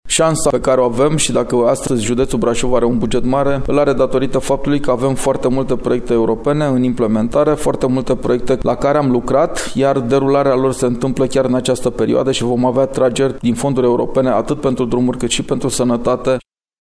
Adrian Veștea, președinte Consiliul Județean Brașov: